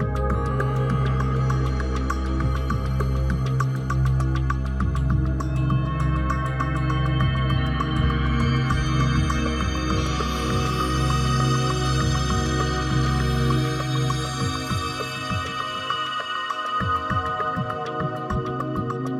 LightPercussiveAtmo7_100_C.wav